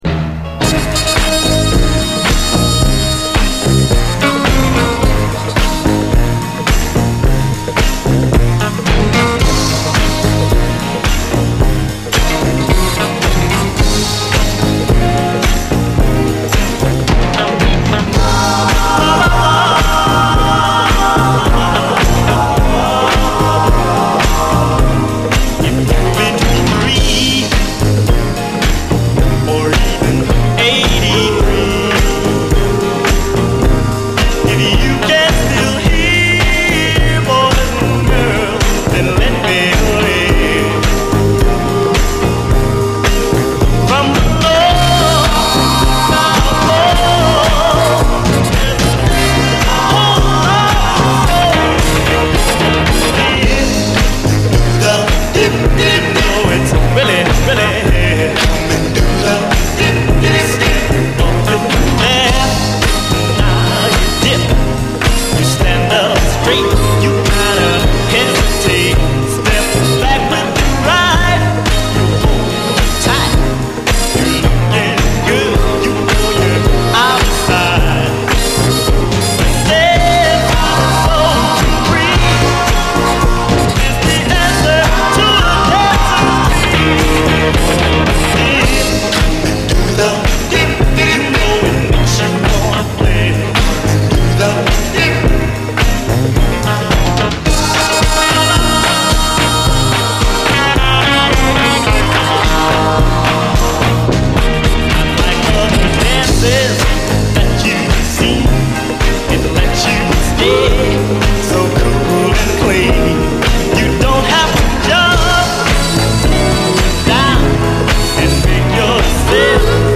SOUL, 60's SOUL
ソウル・ヴォーカル・グループ〜ローライダー・ソウル・ファン必携！